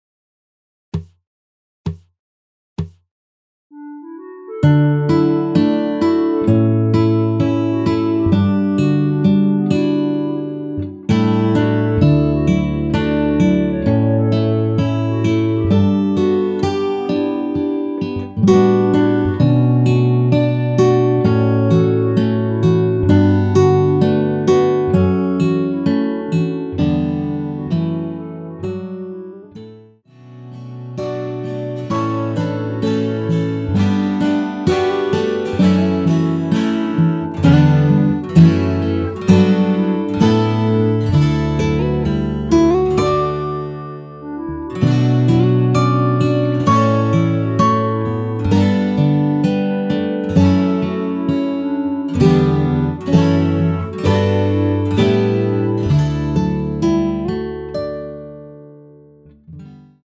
전주없이 시작 하는 곡이라 카운트 넣어 놓았습니다.(미리듣기 참조)
원키에서(-1)내린 (1절+후렴)으로 진행되는 멜로디 포함된 MR입니다.
D
앞부분30초, 뒷부분30초씩 편집해서 올려 드리고 있습니다.
중간에 음이 끈어지고 다시 나오는 이유는